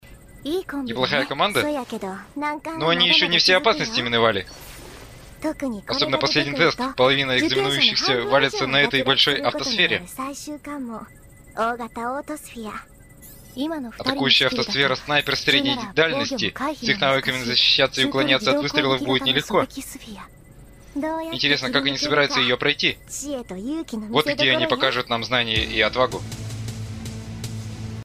На данный момент лично я отмечаю следующие недочёты: недостаточная эмоциональность озвучки, оговорки (небольшое количество), нечёткий тайминг и ещё бы хотелось почётче дикцию (временами она сходит на бубнёж)
Вроде вполне себе нормальный голос без видимых (мною) изъянов.